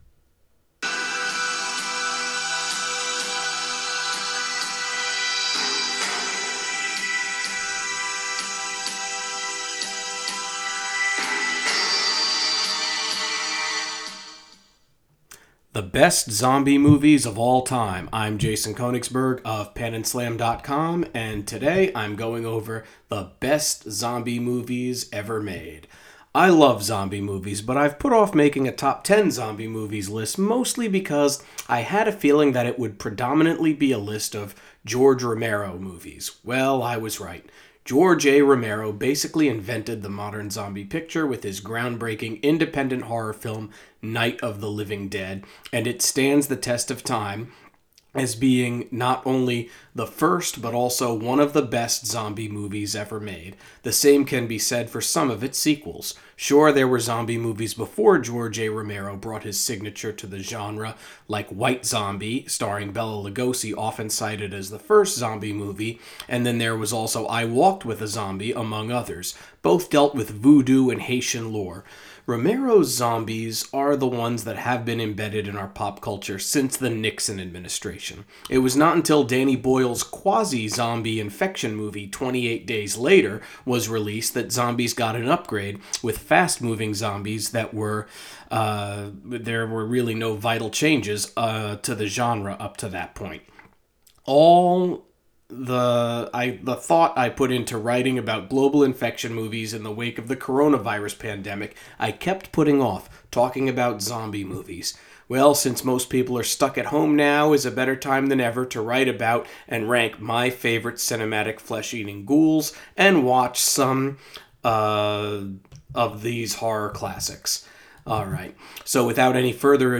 Click play above to hear the article.